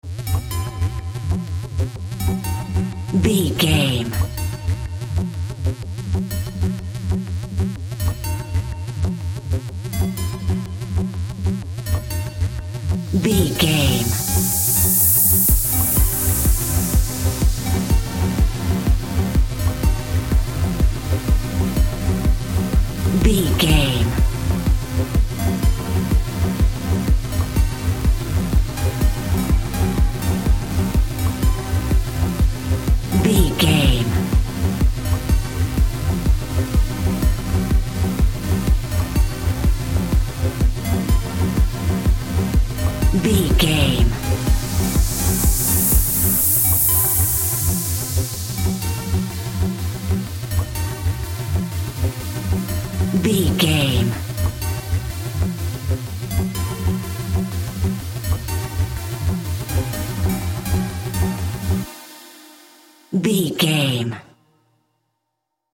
Ionian/Major
E♭
dreamy
ethereal
happy
synthesiser
drums
drum machine
house
techno
trance
synth leads
synth bass
upbeat